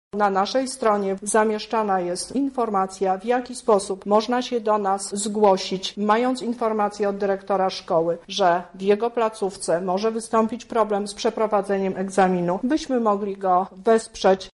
Kurator
-mówi Lubelska Kurator Oświaty, Teresa Misiuk.